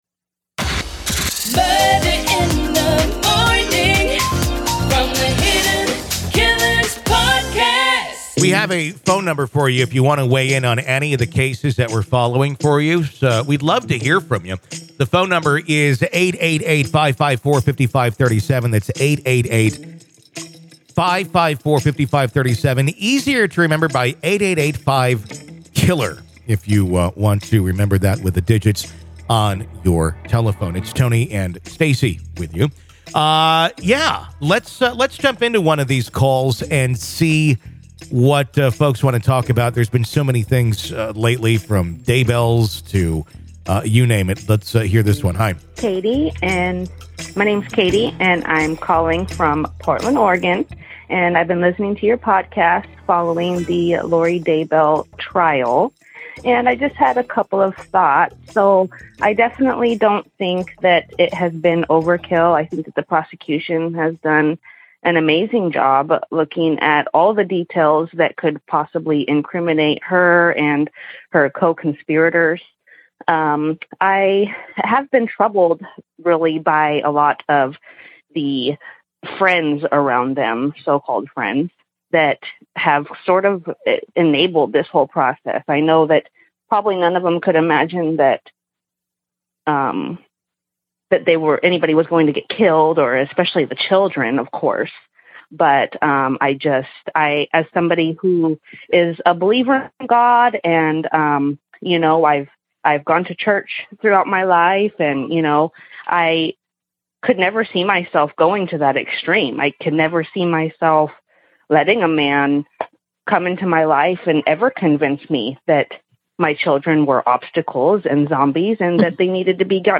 May 17, 2023, 11:00 AM Headliner Embed Embed code See more options Share Facebook X Subscribe In this deeply engaging episode, we delve into the unsettling case of Lori Vallow Daybell, whose actions have provoked widespread shock and perplexity. Our listeners call in, sharing their perspectives and emotions, as we explore the circumstances that led to such a devastating outcome.